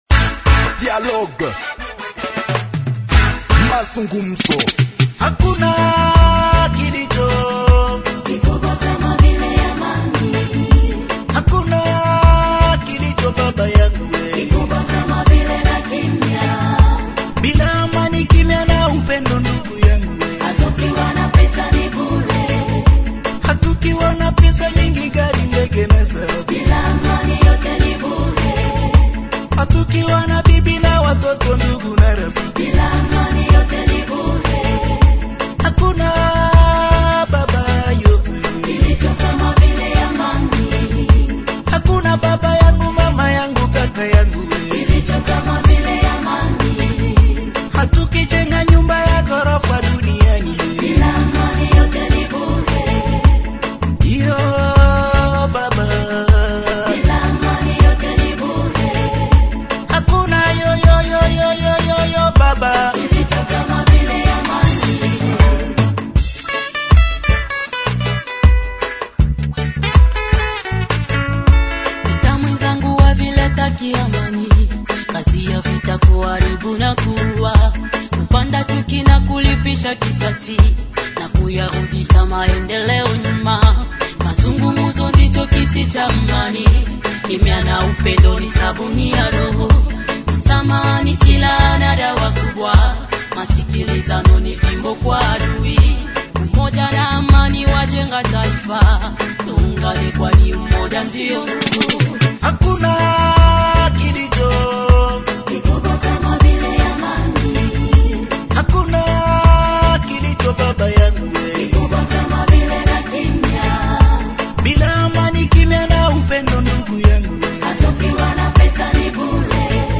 Journal en Français du 05 Mai 2025 – Radio Maendeleo